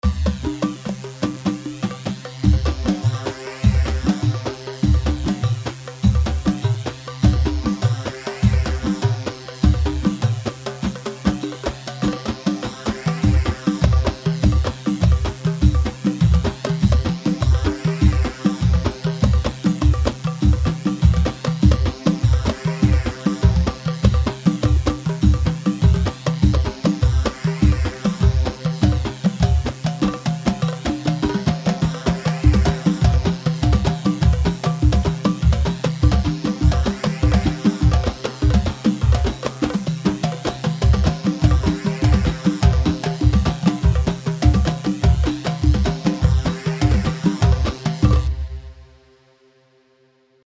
Khbeiti 3/4 150 خبيتي
Khbeiti-3-4-150-L.mp3